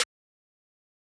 Carti Snare.wav